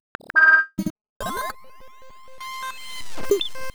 Glitch FX 41.wav